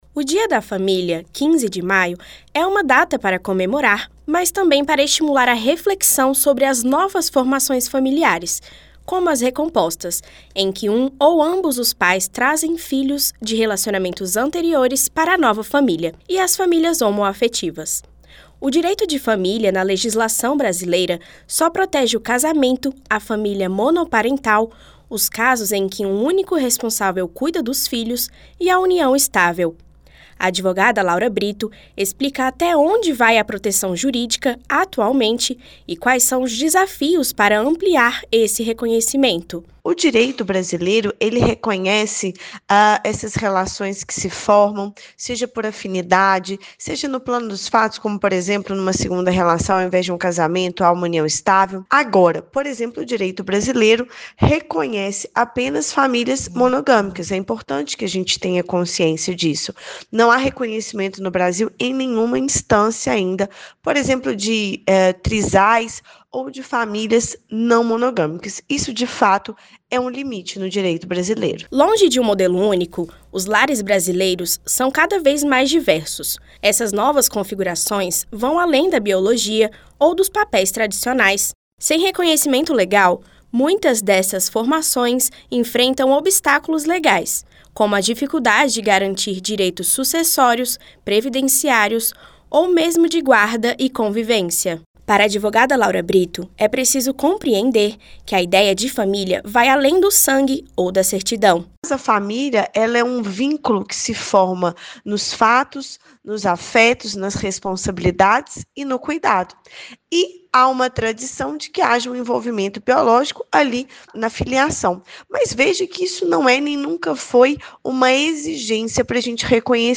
Em entrevista para a Rádio Senado